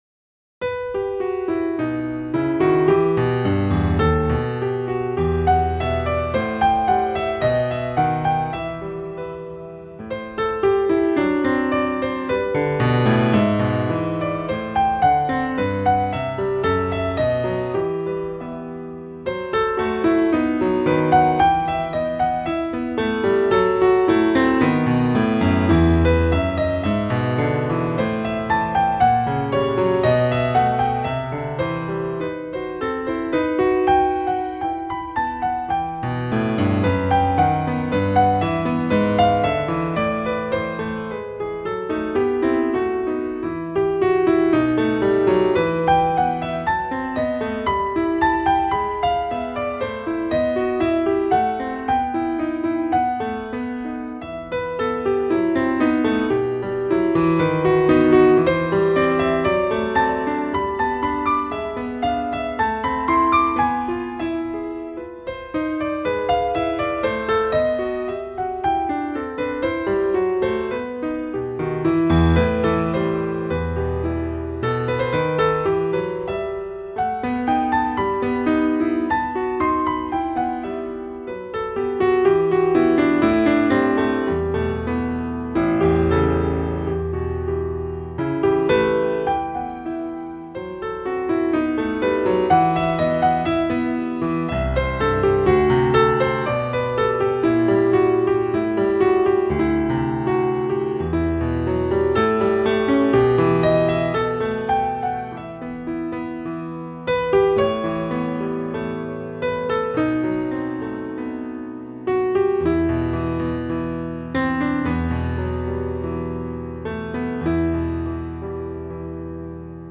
Piano Sonatinas Audio Gallery